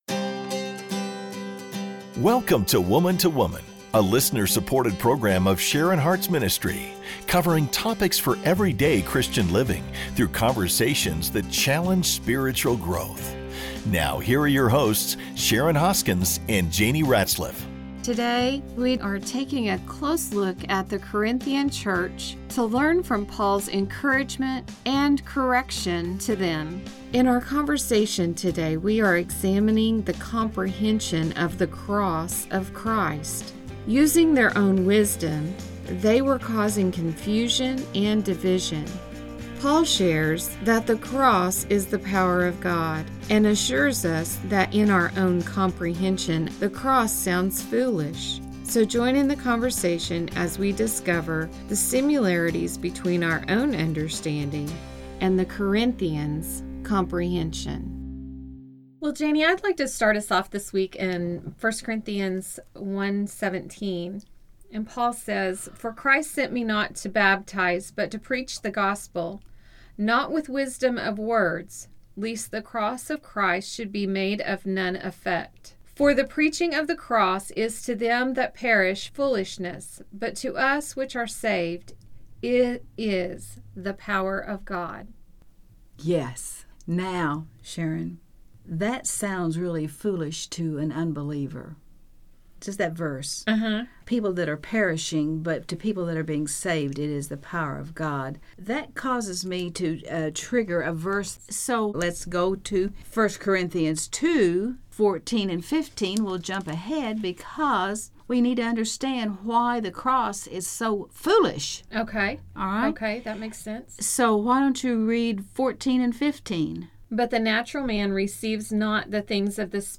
In their conversation, they are examining the comprehension of the Church about the cross of Christ. Paul addresses that they were using their own wisdom, which caused confusion and divisions.